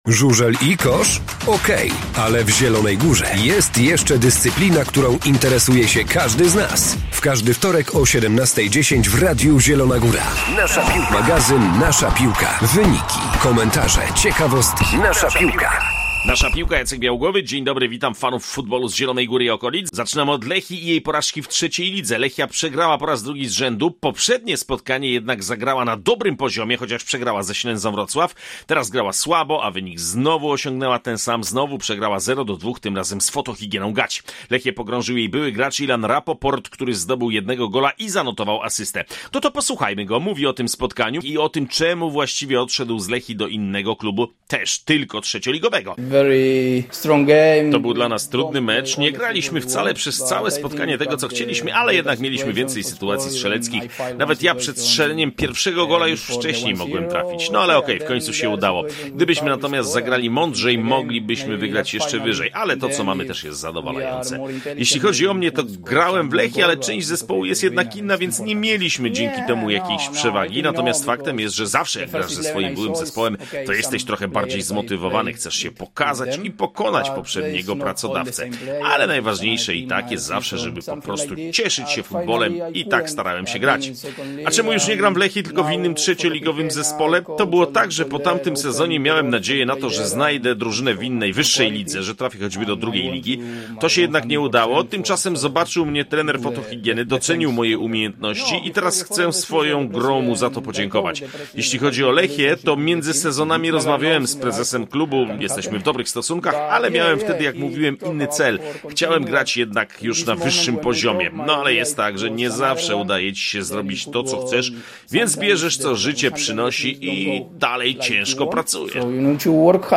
Zapraszamy na cotygodniowy magazyn piłkarski Nasza piłka.
Do tego jesteśmy w klasie A na derbowym meczu Sparta Łężyca – Ikar Zawada oraz w Świdnicy na meczu KP z Bobrem Bobrowice.